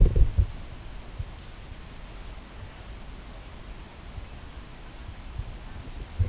Ruf von Tettigonia cantans, Dresden, 11.09.2020, unbearbeitete Originalaufnahme
Imago Männchen